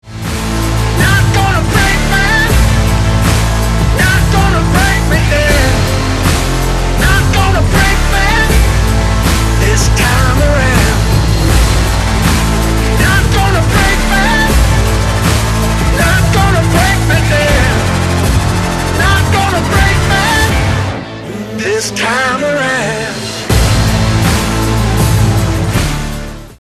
• Качество: 128, Stereo
мужской вокал
alternative
indie rock